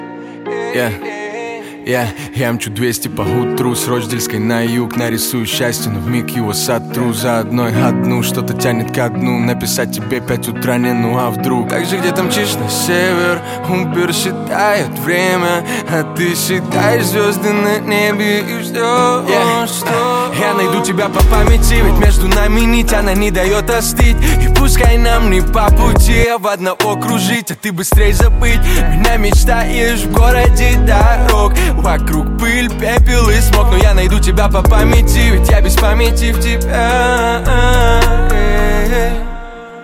Музыка » Rap/Hip-Hop/R`n`B » Хип-хоп викторина